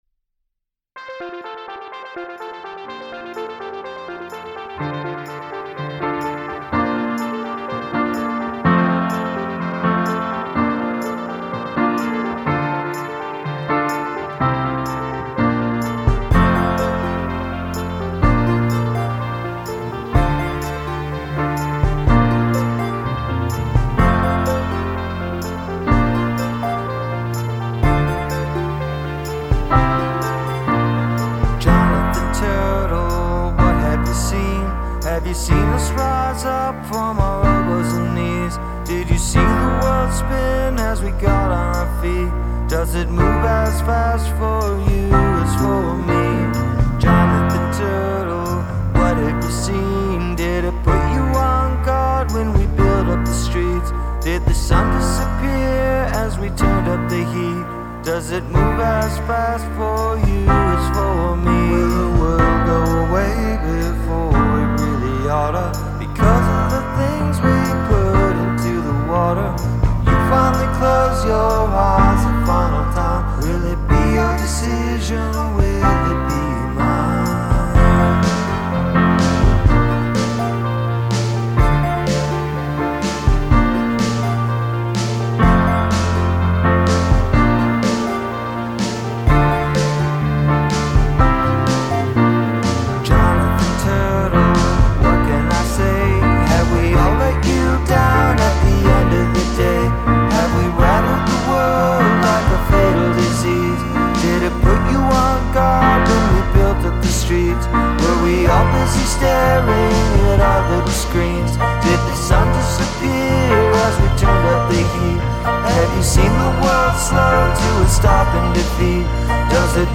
performed his second song of the evening, “Jonathan Turtle” inspired by the 188 year old Jonathan Tortoise from St Helena.